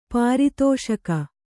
♪ pāri tōṣaka